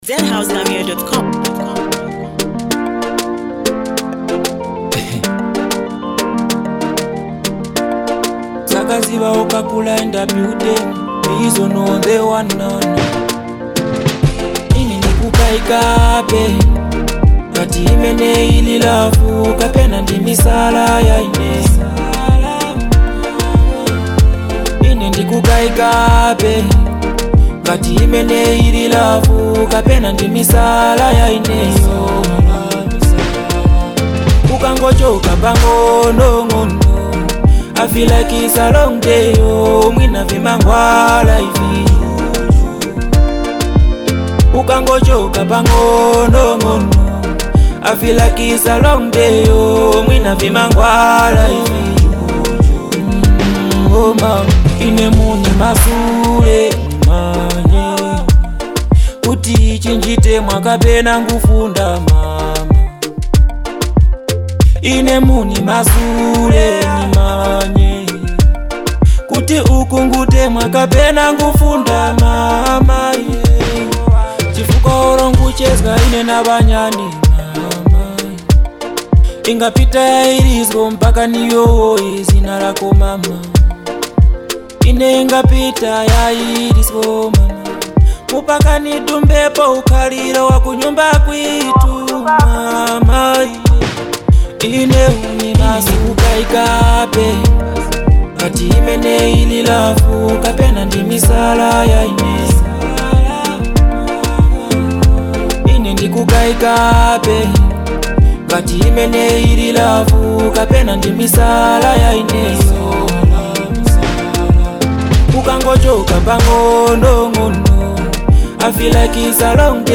bold delivery